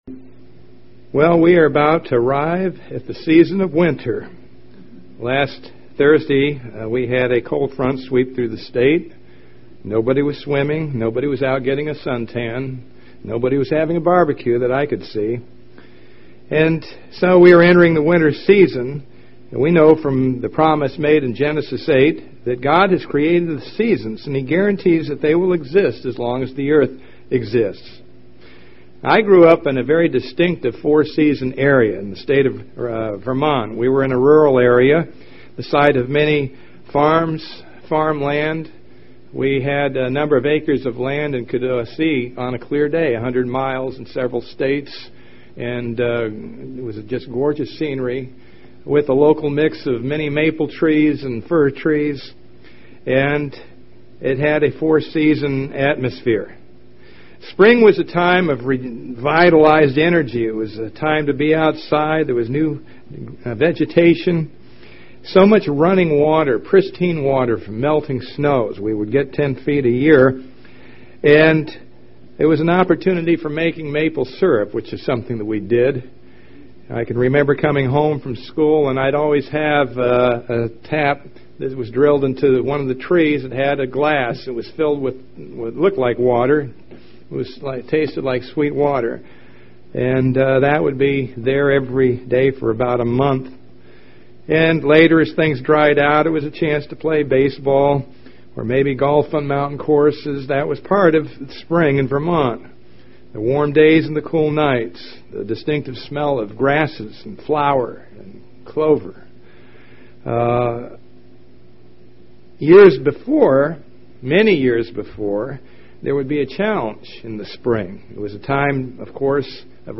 Given in Tampa, FL Orlando, FL
UCG Sermon Studying the bible?